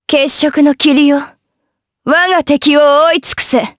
Lewsia_B-Vox_Skill4_jp_b.wav